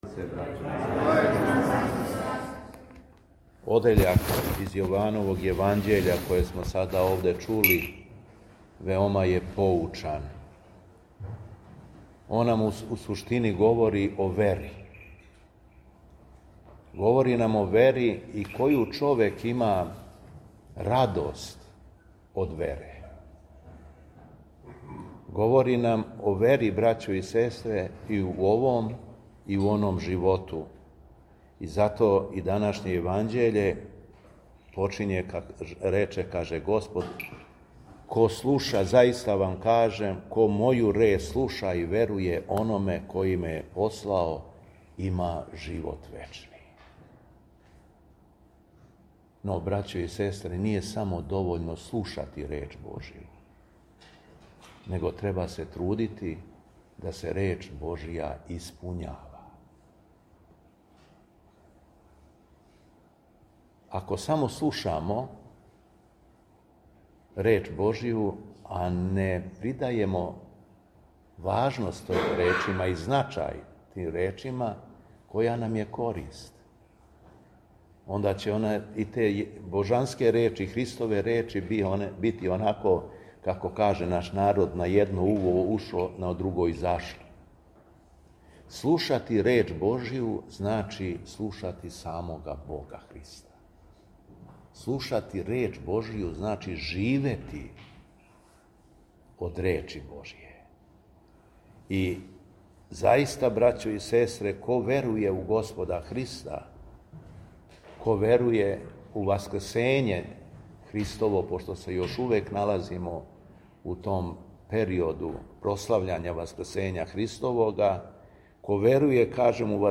ЛИТУРГИЈСКО САБРАЊЕ У СТАРОЈ ЦРКВИ У КРАГУЈЕВЦУ
Беседа Његовог Високопреосвештенства Митрополита шумадијског г. Јована